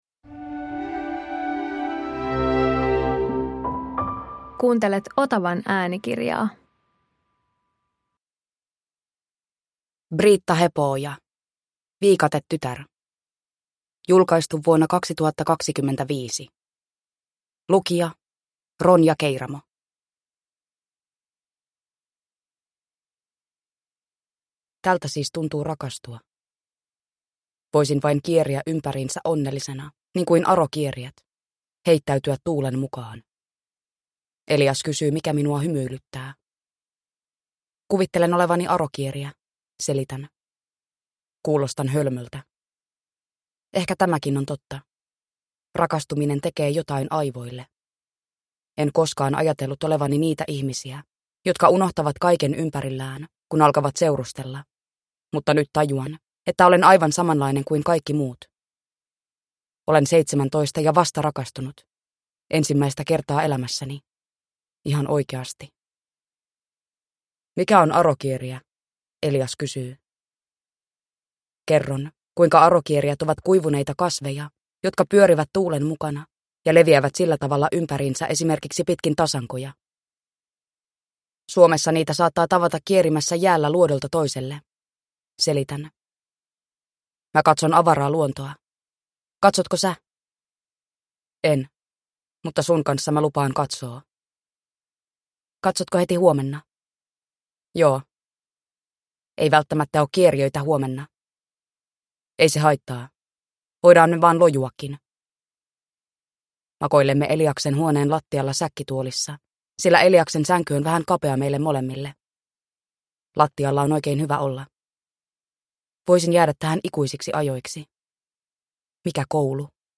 Viikatetytär (ljudbok) av Briitta Hepo-oja